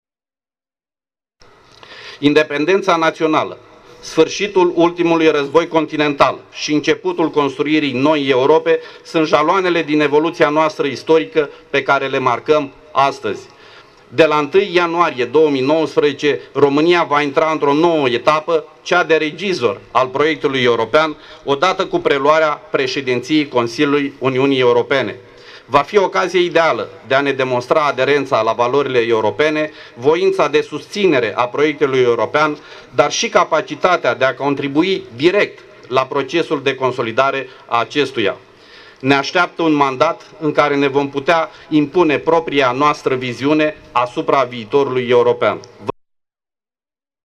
Au urmat apoi vicepresedintele Consiliului Judetean  Victor Chirilă și primarul Iașului, Mihai Chirica.